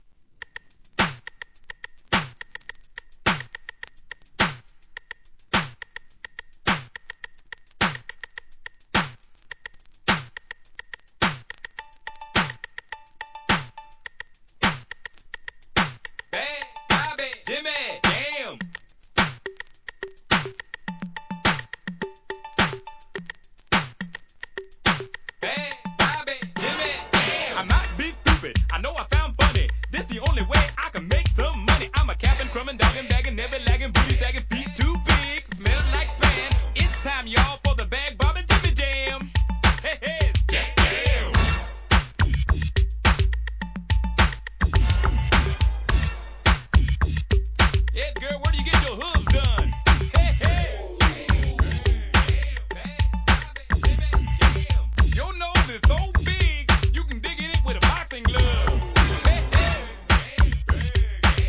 HIP HOP/R&B
WEST COAST エレクトロHIP HOP!!